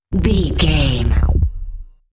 Sound Effects
magical
mystical
special sound effects